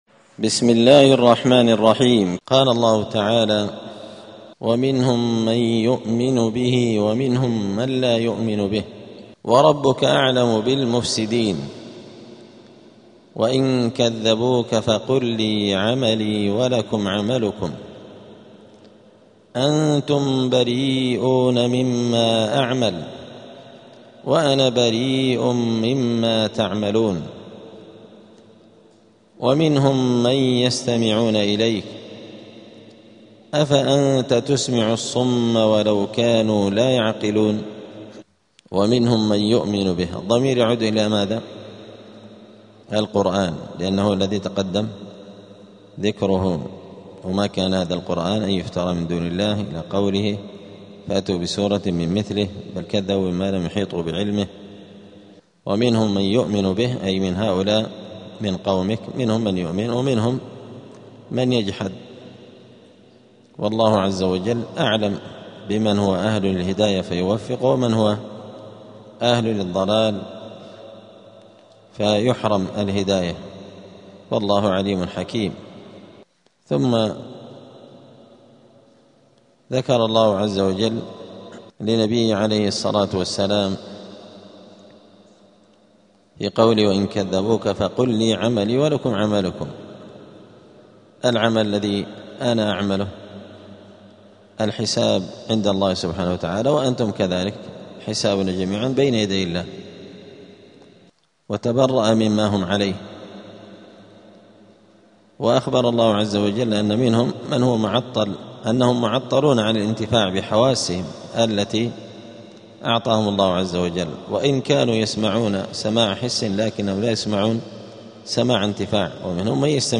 📌الدروس اليومية
دار الحديث السلفية بمسجد الفرقان قشن المهرة اليمن